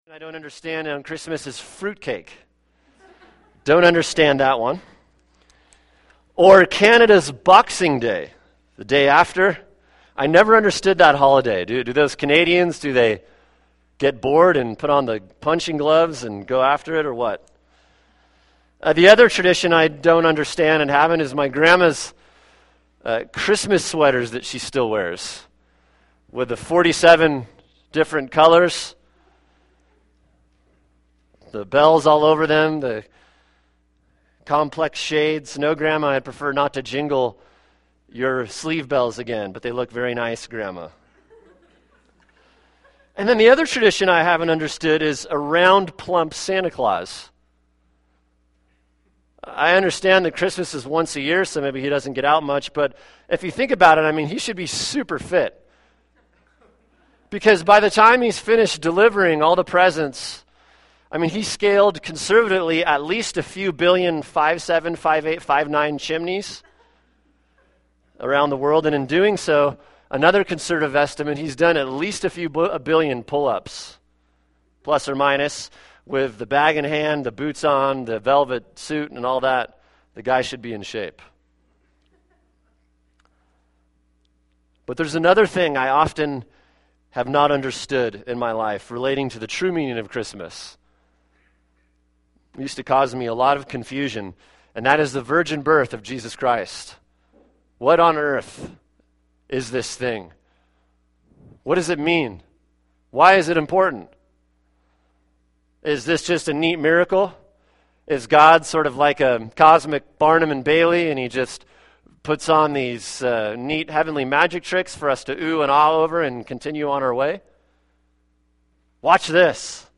[sermon] Christmas Eve – Luke 1:34-35 “Why the Virgin Birth?”